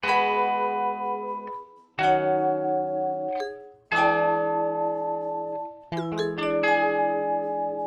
AmajChordswVibes.wav